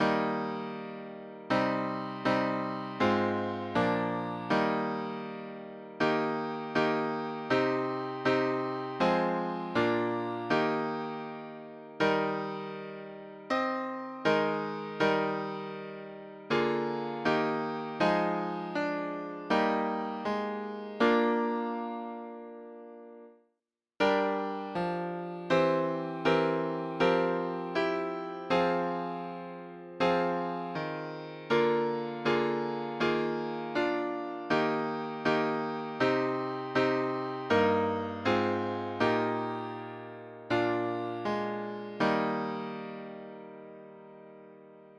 S A T B